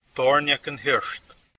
Pronunciation
Pronunciation: [ˈt̪ɔːrˠɲakən ˈhirˠʃt̪]